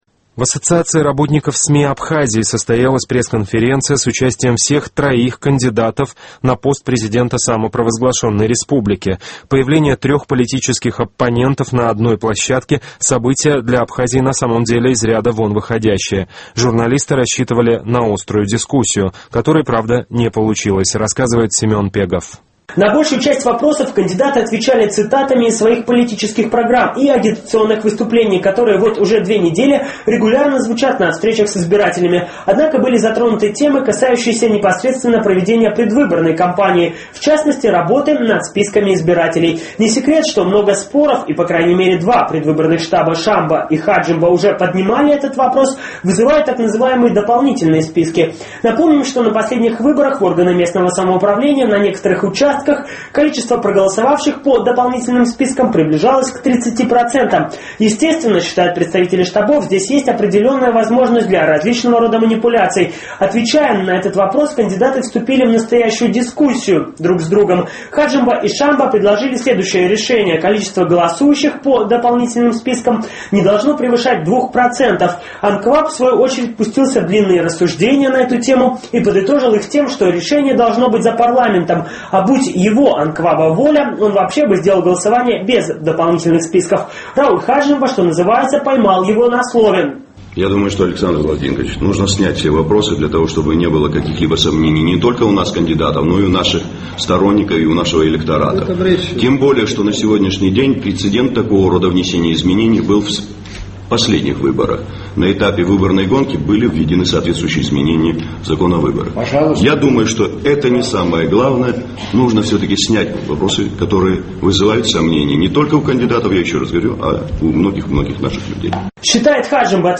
В Ассоциации работников СМИ Республики Абхазия состоялась пресс-конференция с участием всех троих кандидатов на пост президента Абхазии.